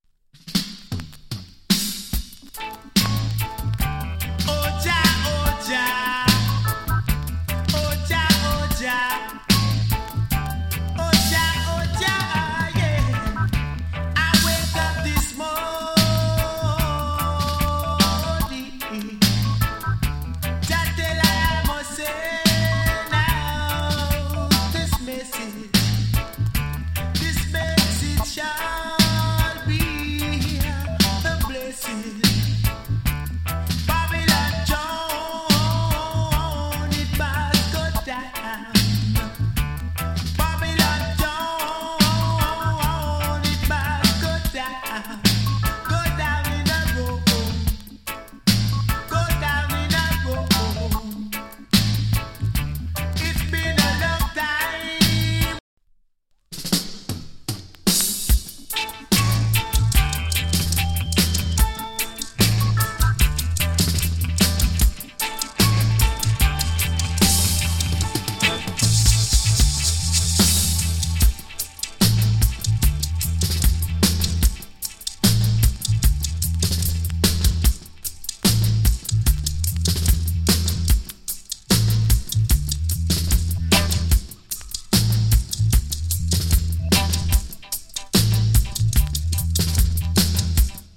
RIDDIM EARLY 80'S ROOTS VOCAL !